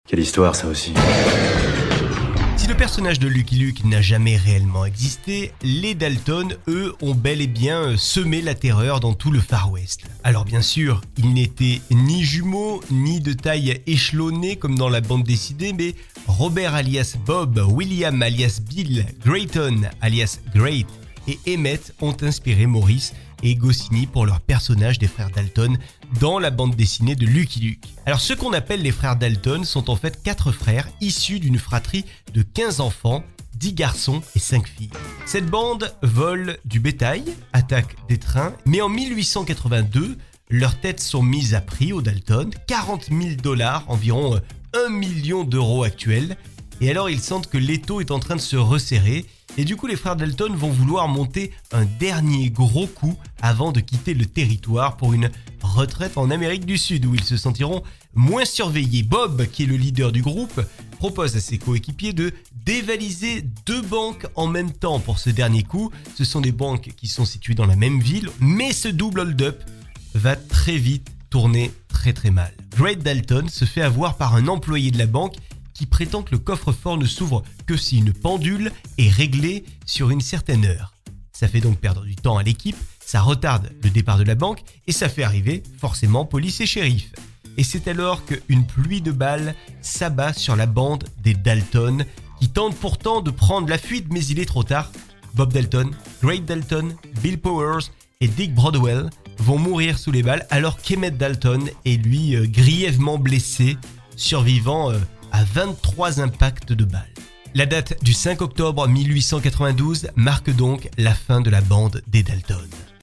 CHRONIQUES POUR RADIO